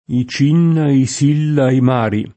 i ©&nna, i S&lla, i m#ri] (Casti) — sim. i cogn. M., De M., Di M.; e lo pseud. E. A. Mario del poeta e musicista Gioviano Gaeta (1884-1961) — Mario anche pers. m. fr. [marL1], port. [m#rLu], sp. [m#rLo] — cfr. Gian Mario